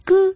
[*3] 初音ミクの声を提供した声優の藤田咲が、ミクのモノマネをしたという記事を見つけたのでリンクしておきます。
初音ミクさんの声ではなく藤田咲さん本人の声の特性です。
「よろしく、ね」の「く」（音程低い）